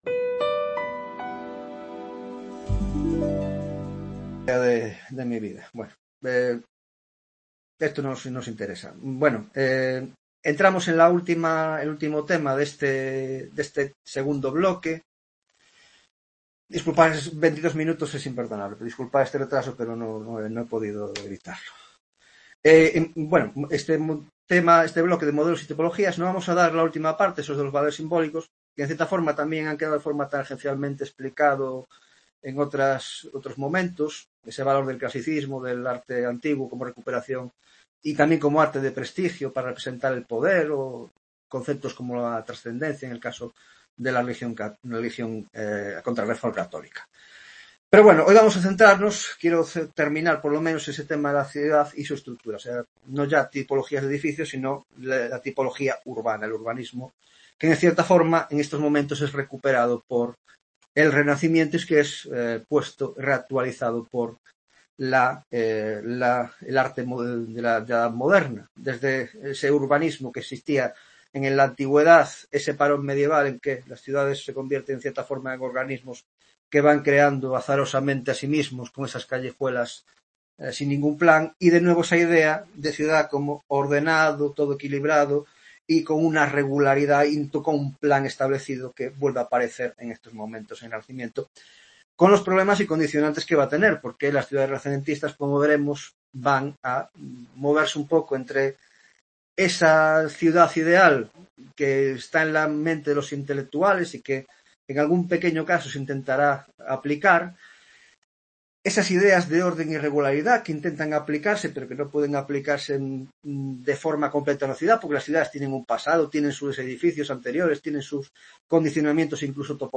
11ª Tutoría de Órdenes y Espacio en la Arquitectura Moderna (grado de Historia del Arte)